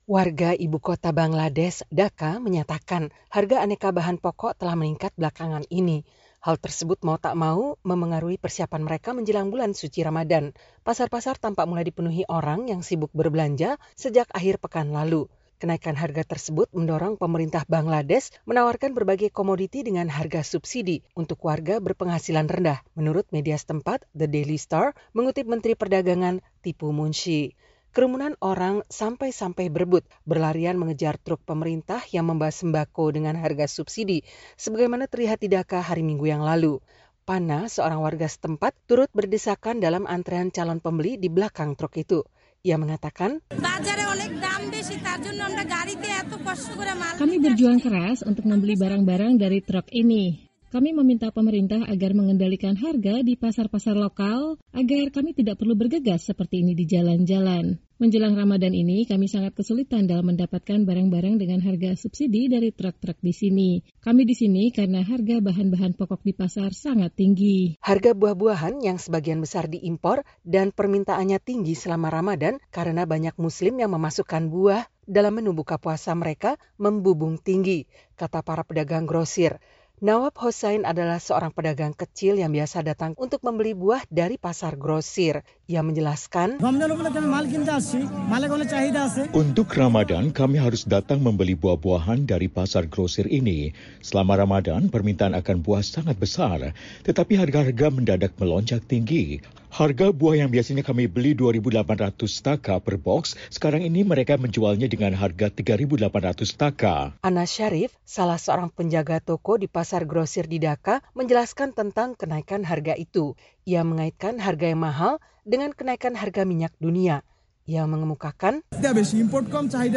Ini antara lain terlihat di kalangan warga Bangladesh yang bergegas berbelanja aneka bahan kebutuhan pokok menjelang Ramadan. Laporan selengkapnya bersama tim VOA.